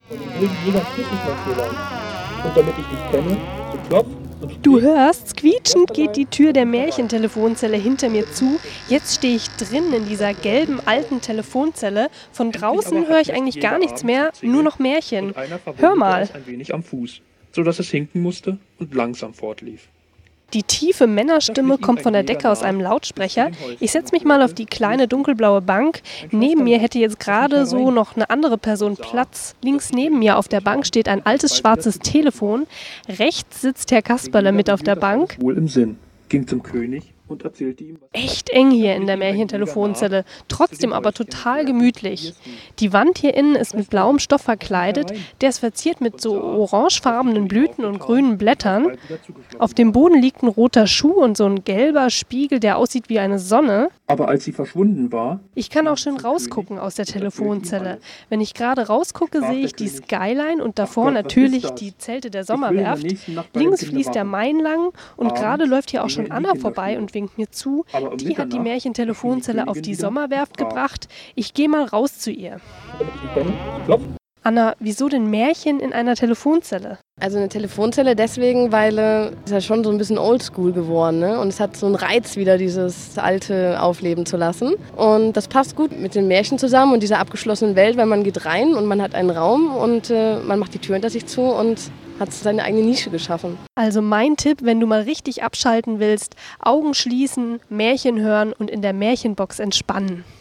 Die Märchentelefonzelle steht direkt vor dem großen Theaterzelt auf der Sommerwerft.